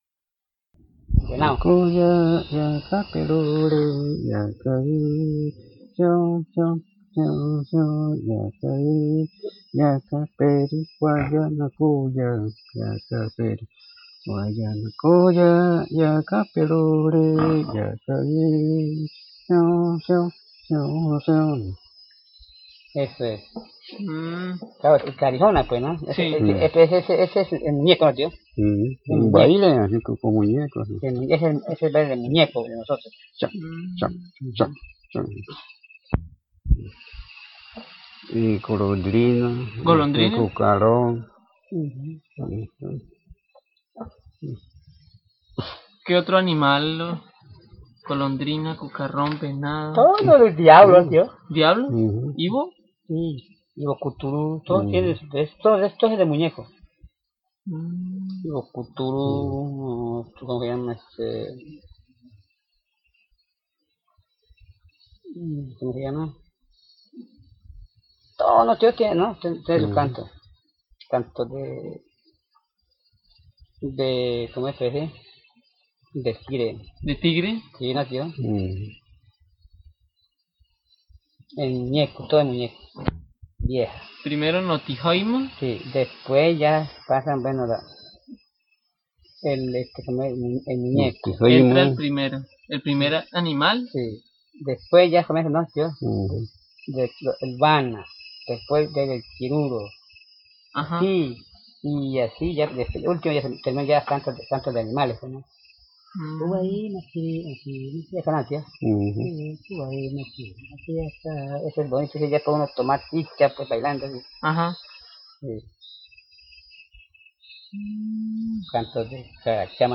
Some songs of the "muñeco" ritual dance (which is celebrated around the stories of the origin of the peach palm) sare sung: chant of the swallow, beetle, deer, iwo (evil spirit) mask, tiger, tucan, cacharama insekt, Notïjëimë —"the old woman"/yuruparí—, resonating tubes (wana), pan flutes. This recording is part of the collection of mythis, stories and songs collected by the Karijona Linguistics Seedbed (Department of Linguistics, Faculty of Human Sciences, Bogotá campus of UNAL) collected from the Karijona people in the Caquetá and Vaupés Rivers between 1985 and 2021 .